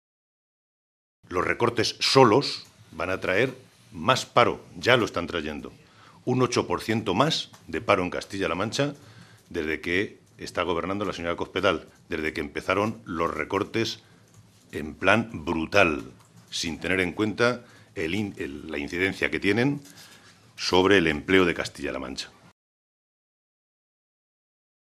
Santiago Moreno, diputado regional del PSOE de Castilla-La Mancha
Cortes de audio de la rueda de prensa